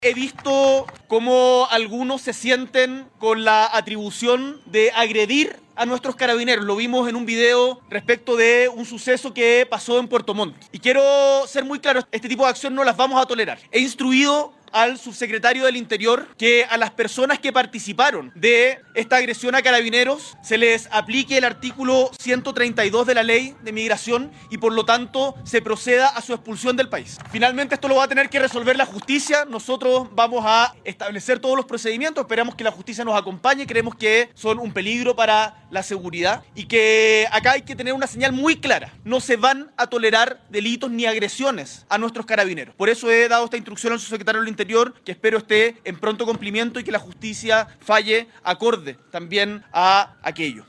Al respecto el primer mandatario, fue enfático en señalar que no se van a tolerar agresiones a Carabineros de Chile: